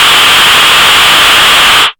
RADIOFX  7-L.wav